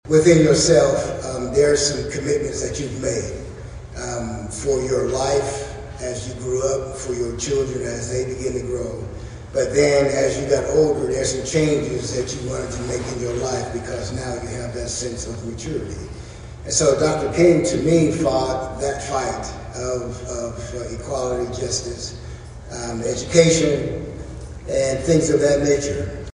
To “remain committed to the change” was the theme for Sunday night’s rescheduled Martin Luther King Jr Celebration, held at First United Methodist Church and organized by the Eastside Community Group.